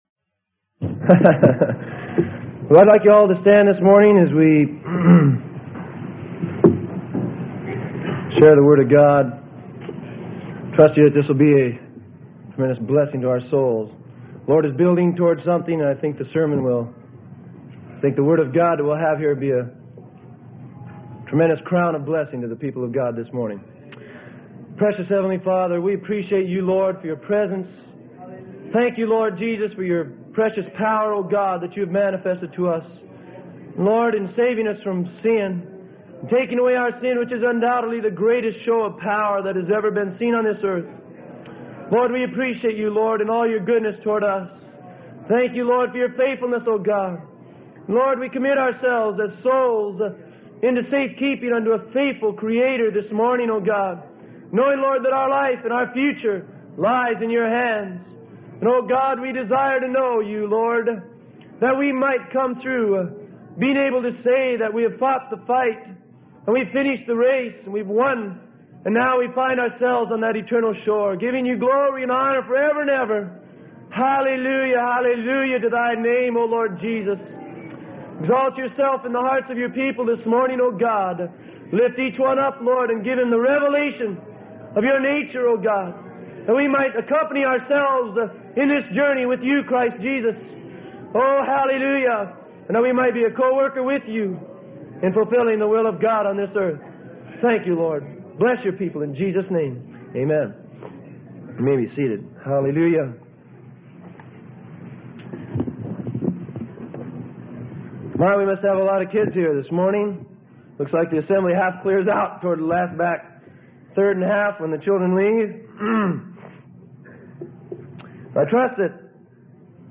Sermon: The Crown of the Believer - Freely Given Online Library